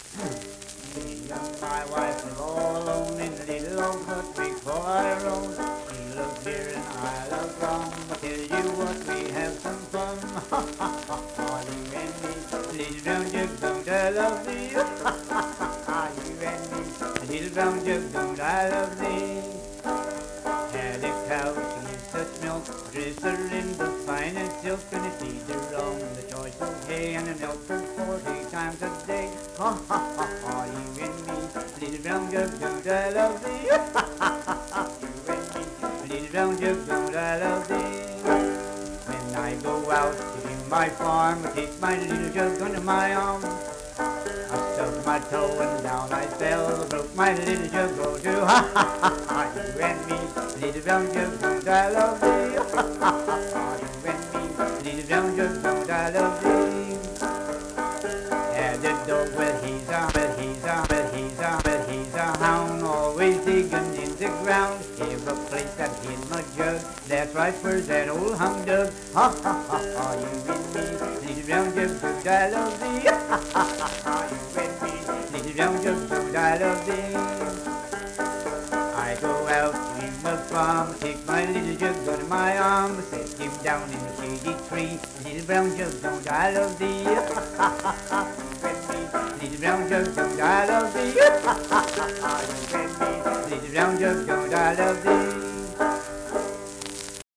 Banjo accompanied vocal music performance
Verse-refrain 5(4) & R(4).
Dance, Game, and Party Songs
Voice (sung), Banjo